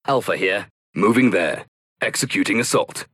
Grey Goo Alpha Voice Lines